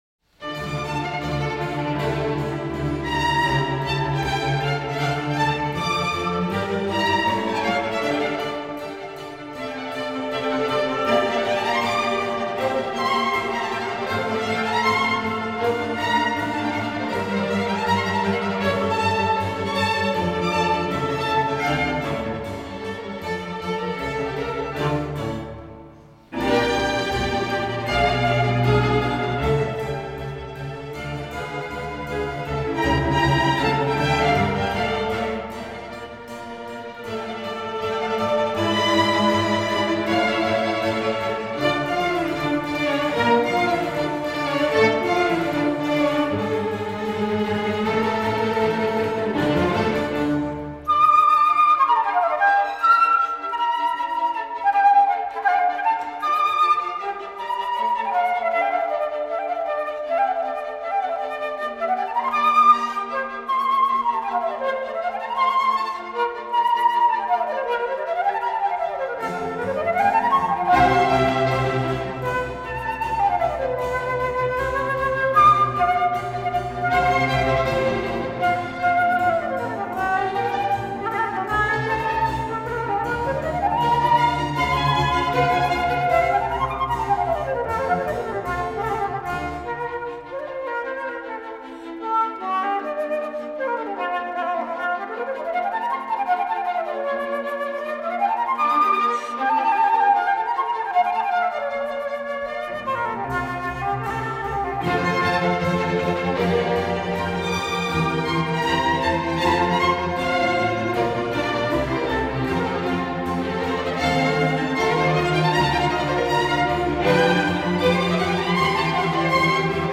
Latvijas filharmonijas kamerorķestris, izpildītājs
Mūzikas ieraksts
Koncertžanrs
Rīga, Vāgnerzāle